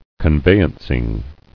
[con·vey·anc·ing]